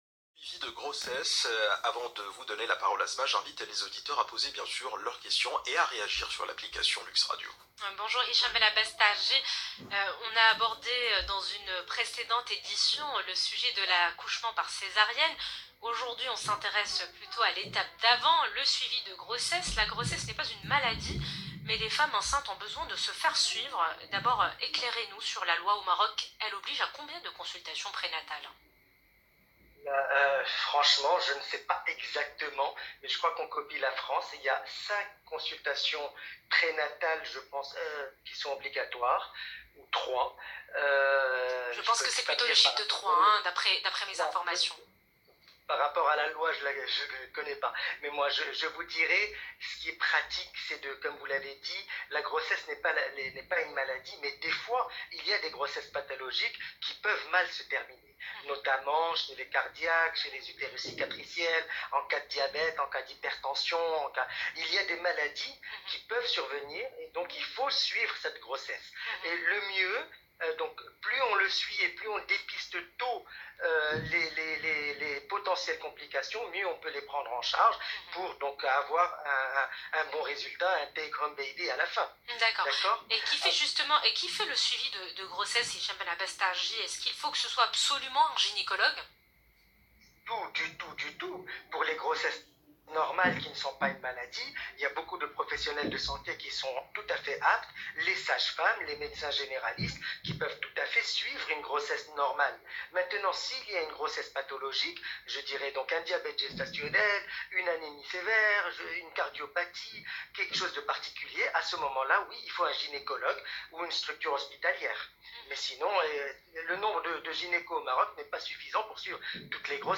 Est ce que la répétition des échographie n’est pas dangereuse pour mon bébé? Et avec le coronavirus, vaut il mieux ne pas sortir? Vos réponses dans cette interview